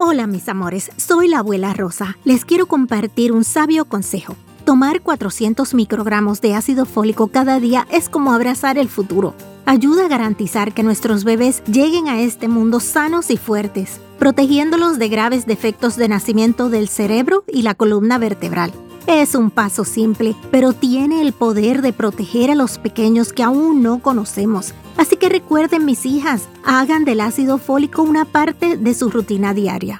Public service announcement sharing a tip from abuela Rosa: Start taking folic acid today.